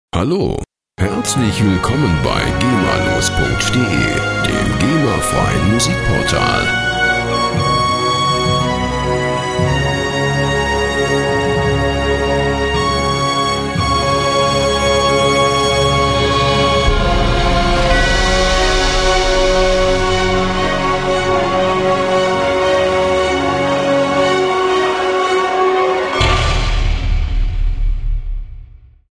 Werbemusik Loops für Ihr Unternehmen
Musikstil: Soundtrack
Tempo: 58 bpm